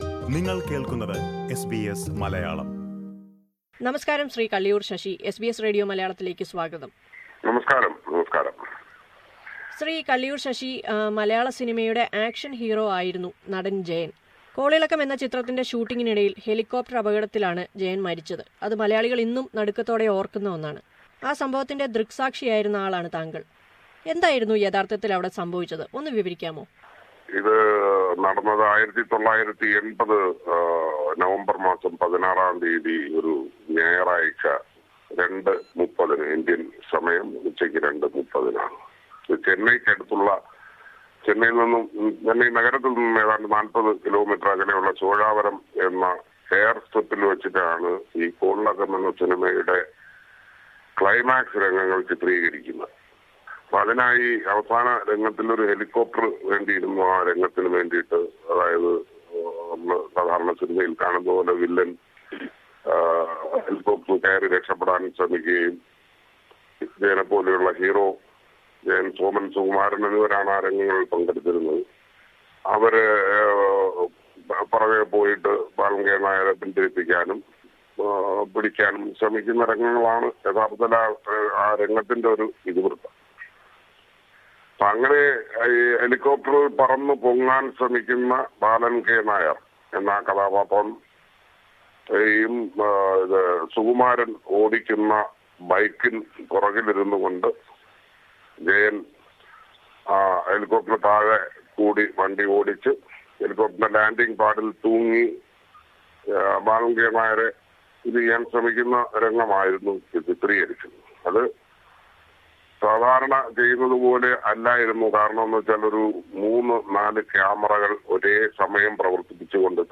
ജയന്റെ 40 ആം ചരമവാർഷികം ആചരിക്കുന്ന ഈ അവസരത്തിൽ ഈ അഭിമുഖം ഒരിക്കൽ കൂടി കേൾക്കാം...